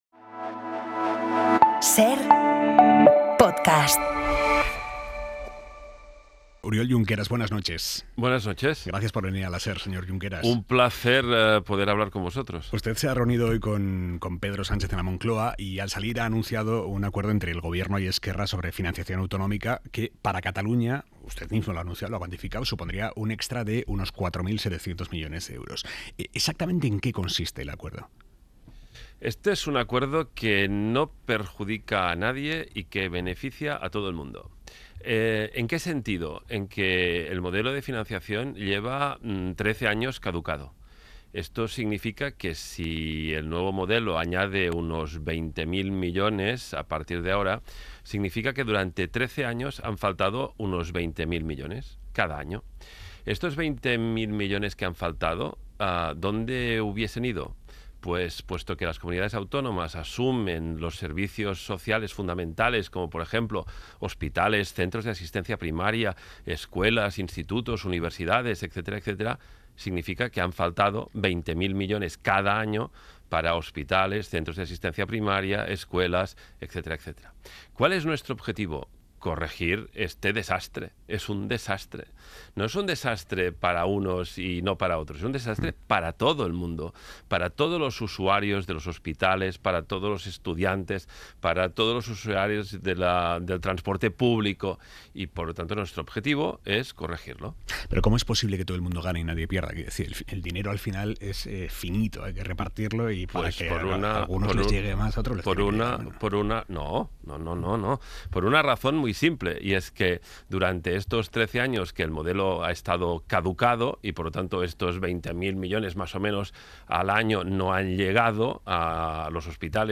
Aimar Bretos entrevista a Oriol Junqueras, presidente de Esquerra Republicana de Catalunya.